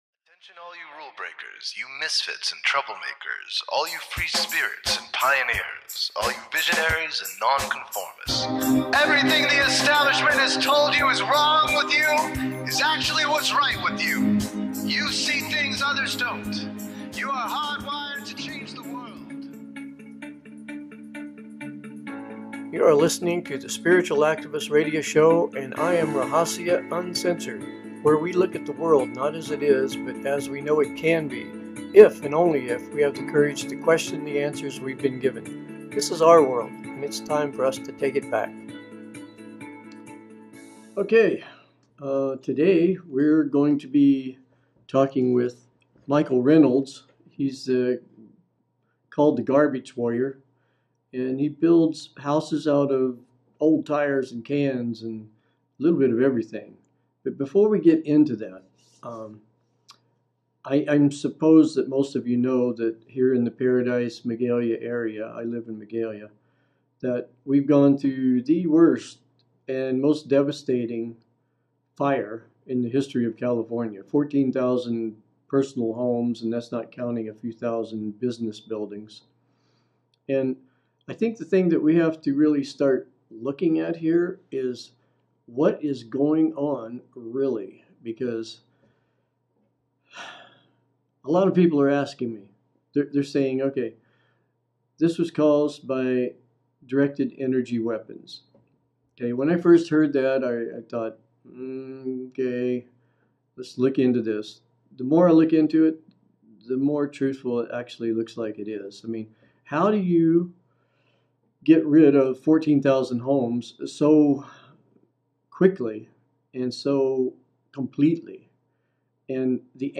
Guest, Michael Reynolds who is known as the Garbage Warrior and builds Earthships